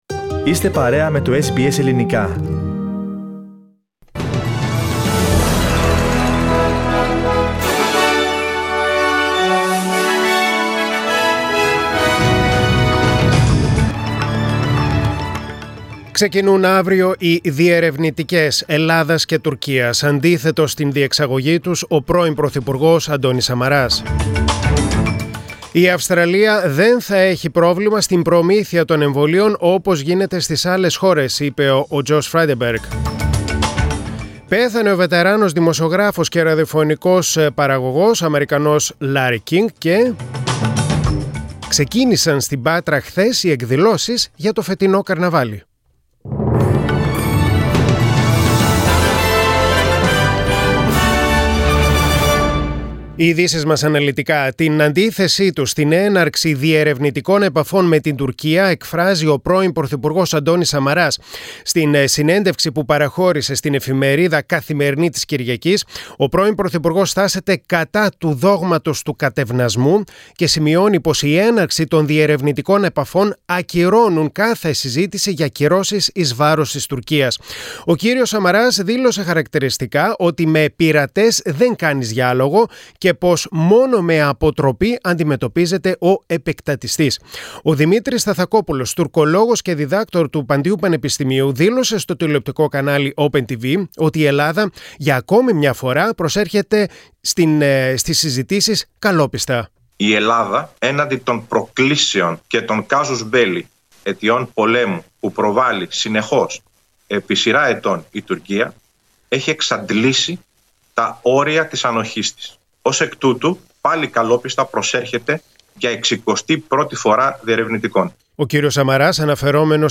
Press Play on the main photo and listen the News Bulletin (in Greek) Source: SBS Greek